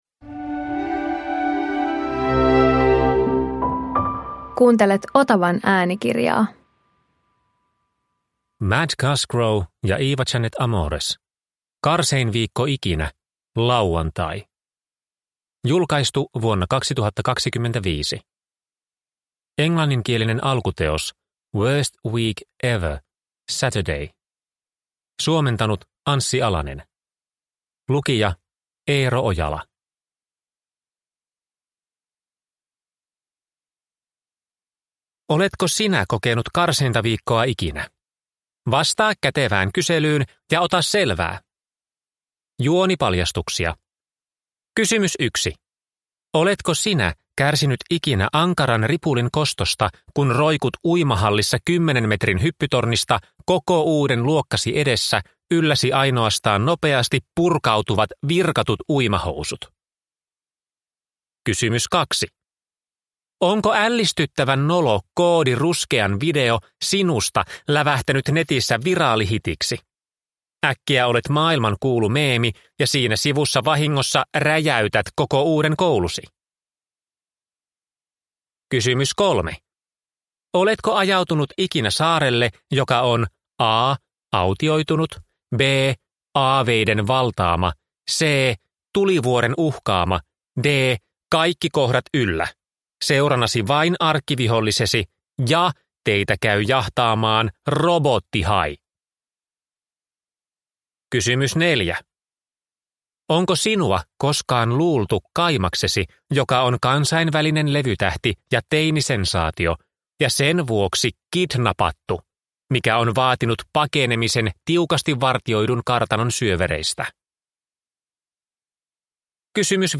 Karsein viikko ikinä: lauantai – Ljudbok